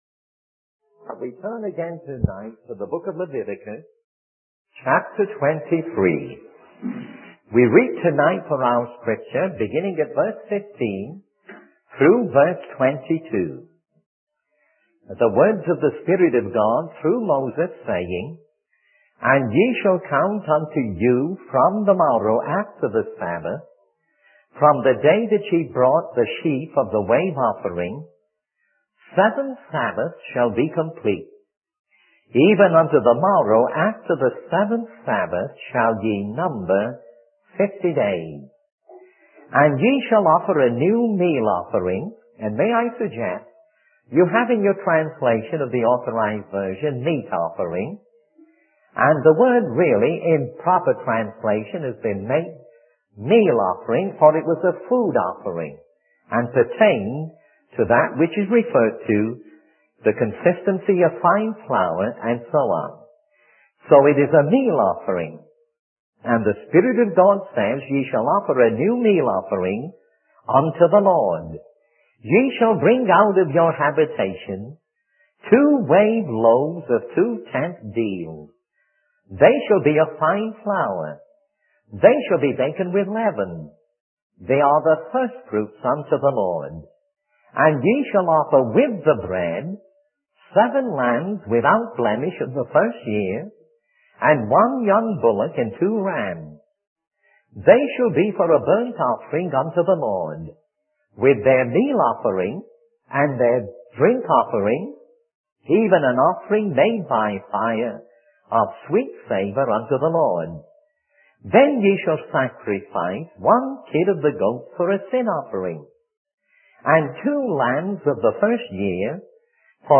In this sermon, the speaker begins by discussing a biblical commandment regarding the harvest of the land.